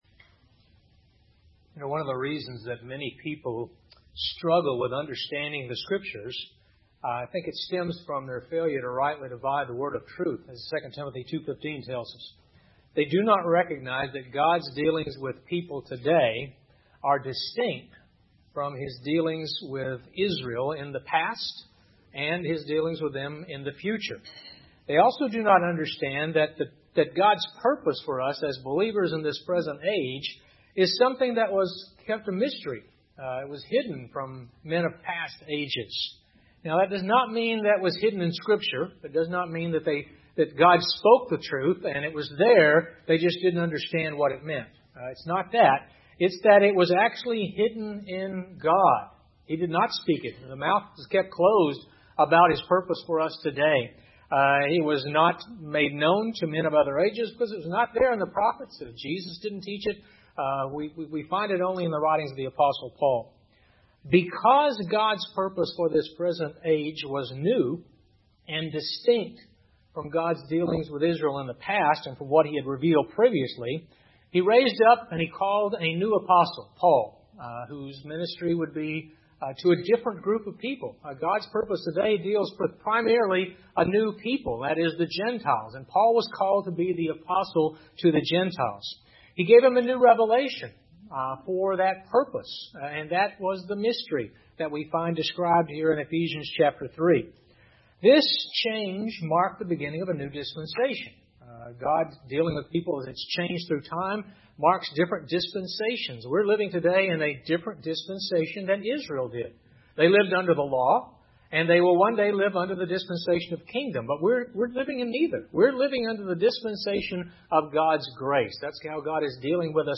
A message from the series "Ephesians."